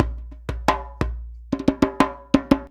089DJEMB06.wav